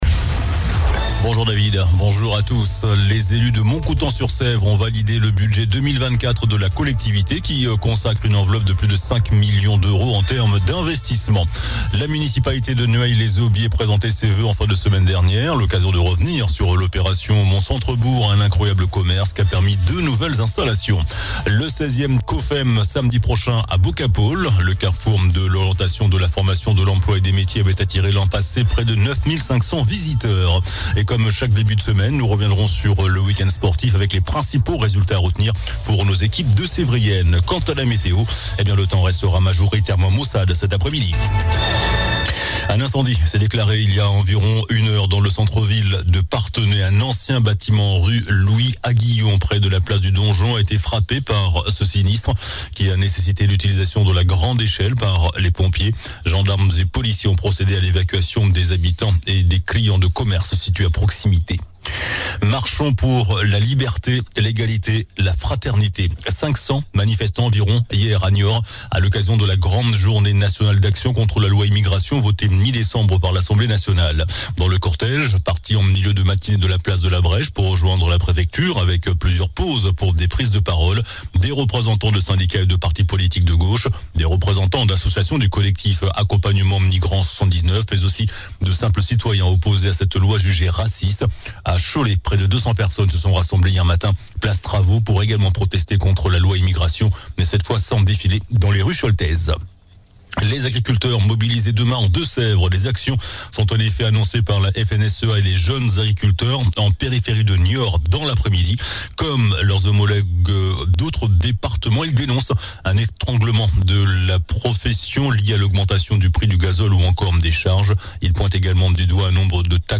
JOURNAL DU LUNDI 22 JANVIER ( MIDI )